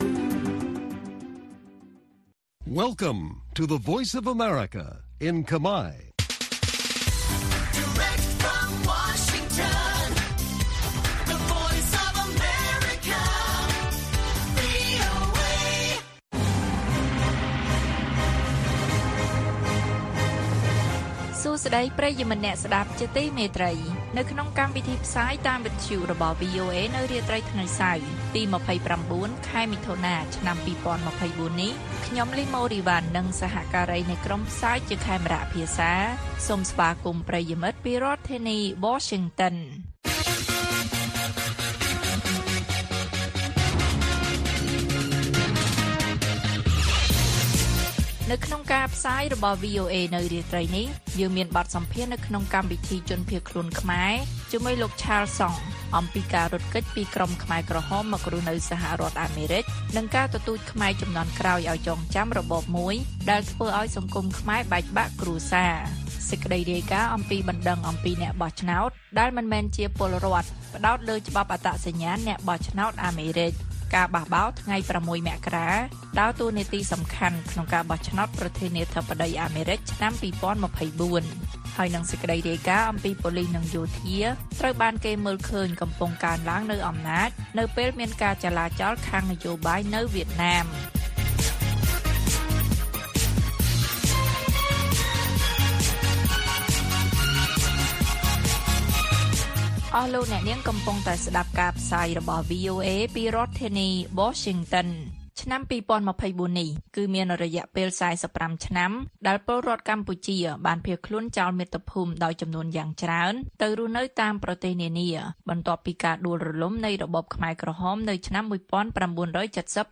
ព័ត៌មាននៅថ្ងៃនេះមានដូចជា បទសម្ភាសន៍នៅក្នុងកម្មវិធីជនភៀសខ្លួនខ្មែរ៖ សកម្មជនសហគមន៍រៀបរាប់ពីភាពភ័យរន្ធត់ពេលរត់គេចខ្លួនពីរបបខ្មែរក្រហមមកអាមេរិក។ បណ្តឹងអំពីអ្នកបោះឆ្នោតដែលមិនមែនជាពលរដ្ឋផ្តោតលើច្បាប់អត្តសញ្ញាណអ្នកបោះឆ្នោតអាមេរិក និងព័ត៌មានផ្សេងៗទៀត៕